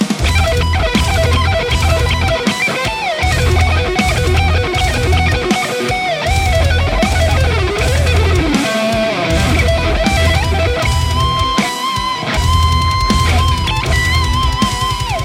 Lead Mix
RAW AUDIO CLIPS ONLY, NO POST-PROCESSING EFFECTS
Hi-Gain